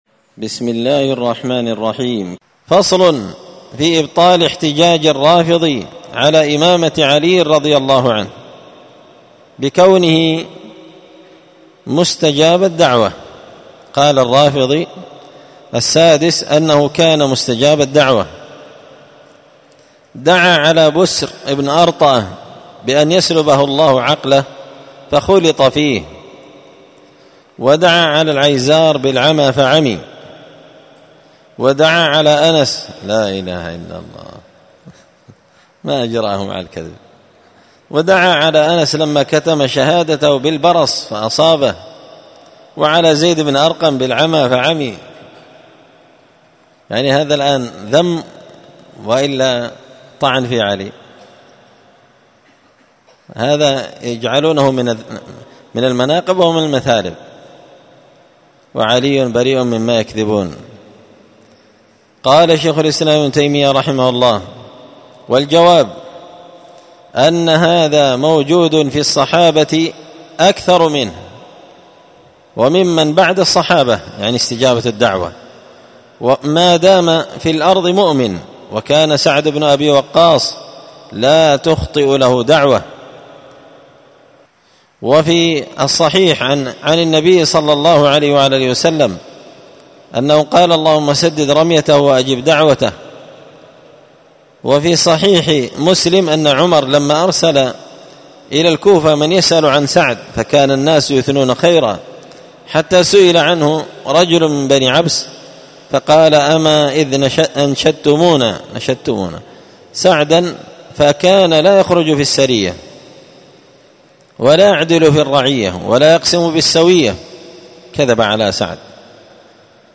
الخميس 6 ربيع الأول 1445 هــــ | الدروس، دروس الردود، مختصر منهاج السنة النبوية لشيخ الإسلام ابن تيمية | شارك بتعليقك | 14 المشاهدات
مسجد الفرقان قشن_المهرة_اليمن